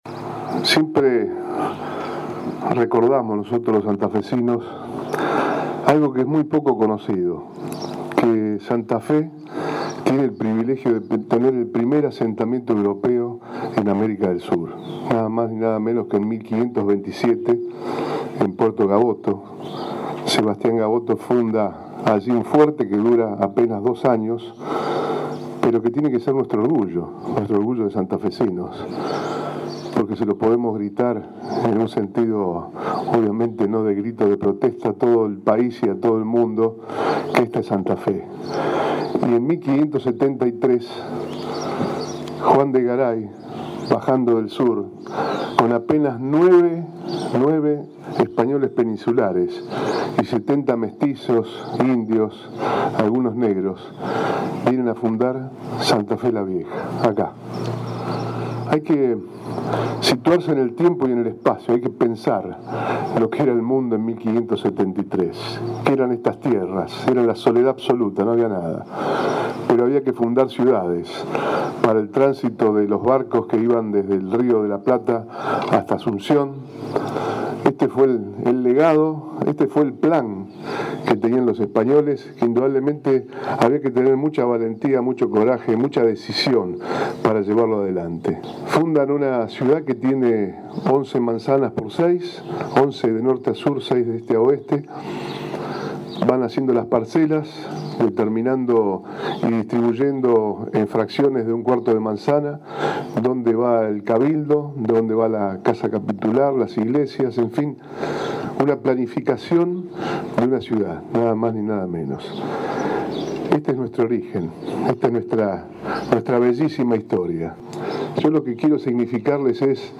El acto, encabezado por el gobernador, se llevó a cabo en Cayastá.